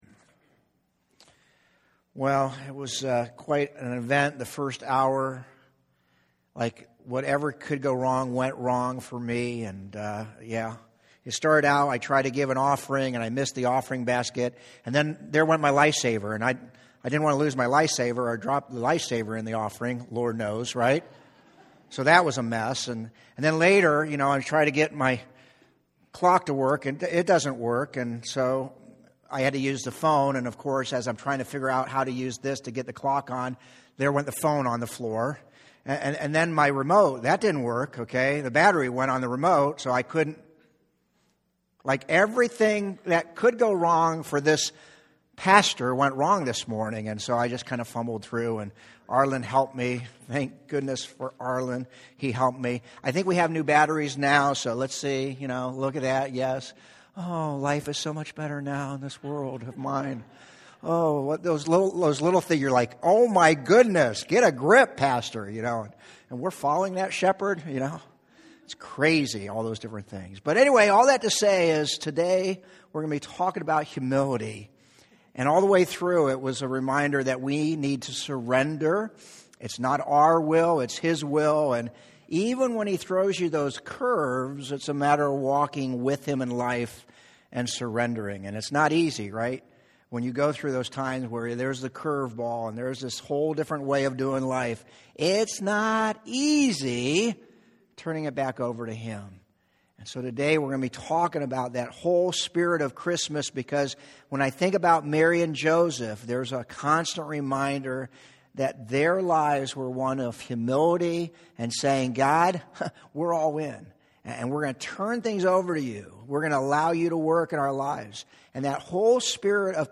Medina Community Church Sermons